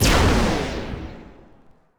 lasers.wav